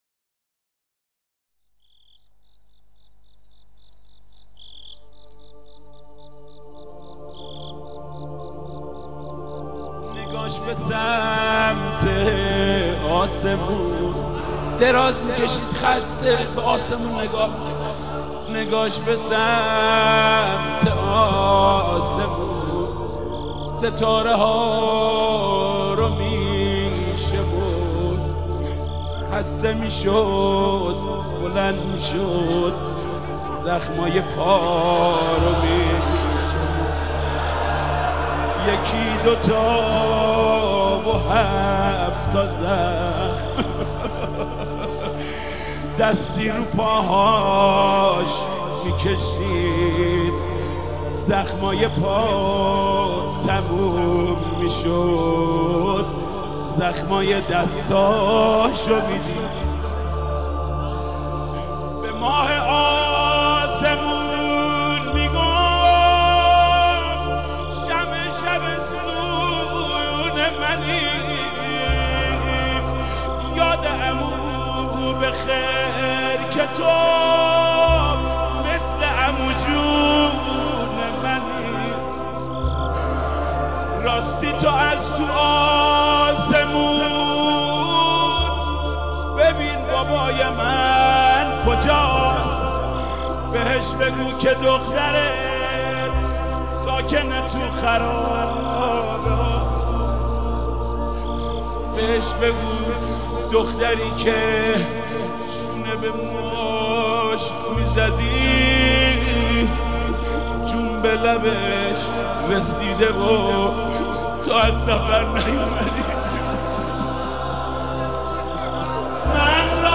یه روضه قدیمی برای حضرت رقیه است [audio mp3="/uploads/2016/10/ChashmHa-.mp3"][/audio] لینک دانلود ◄ حجم تقریبا دو نیم مگا بایت